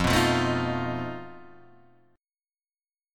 F 7th Flat 5th